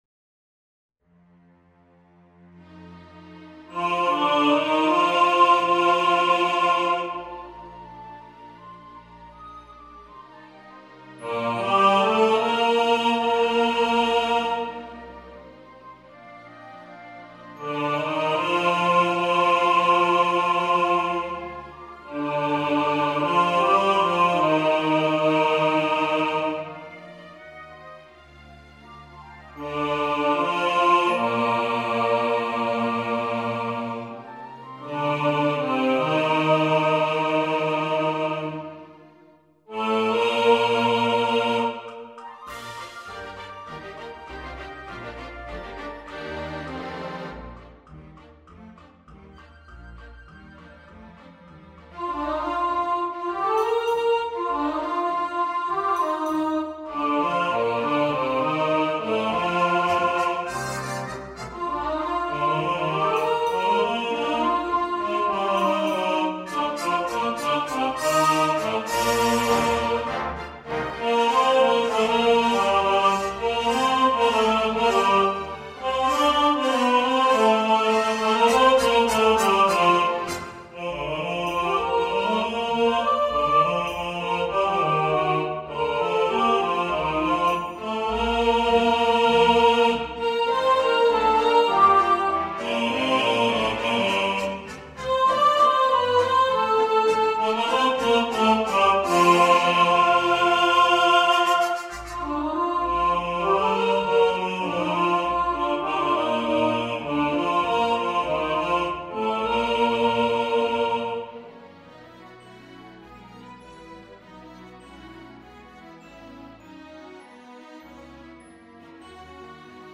Santa Claus Is Coming To Town All Voices | Ipswich Hospital Community Choir